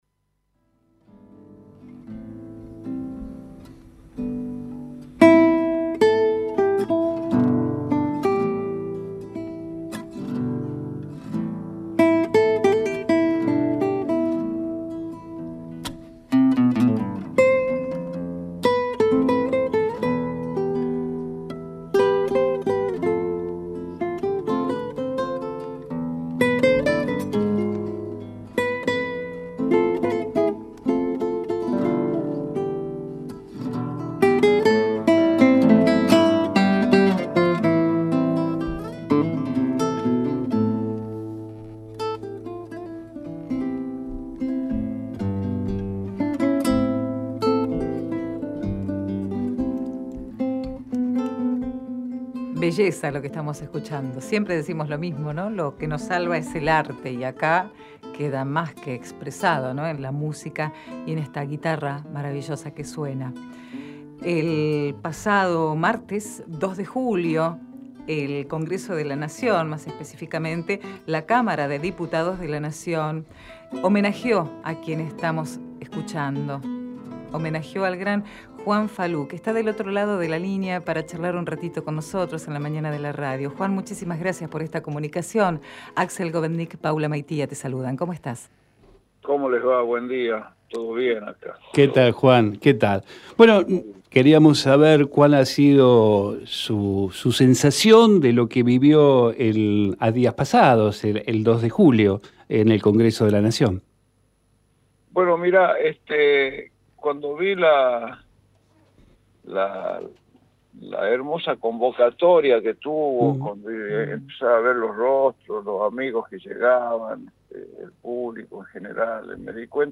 Todo esto fue reconocido por el Congreso de la Nación, en un homenaje en el Salón Delia Parodi. En esta charla con Vivís la UNDAV nos cuenta algunos detalles de ese momento y algunas otras apreciaciones.